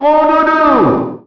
The announcer saying Jigglypuff's name in English releases of Super Smash Bros. Melee.
Jigglypuff_French_Announcer_SSBM.wav